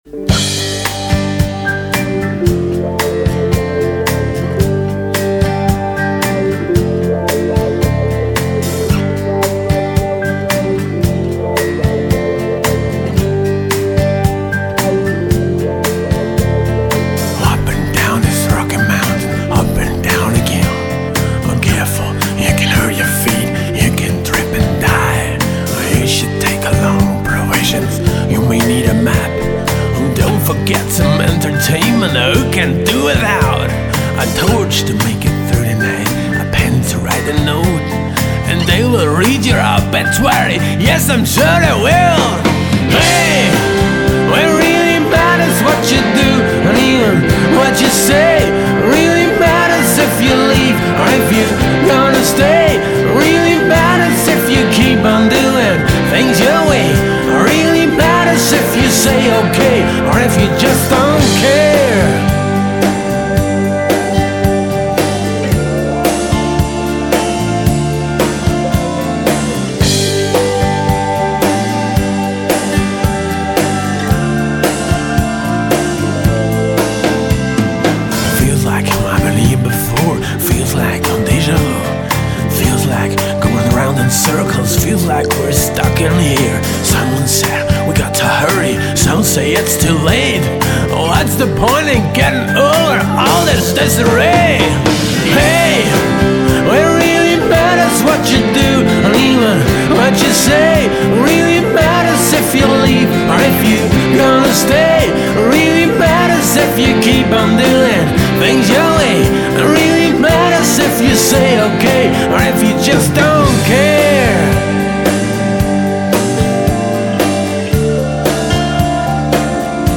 drums
bass
electric guitars
Hammond organ
harmony vocals
vocals and acoustic guitars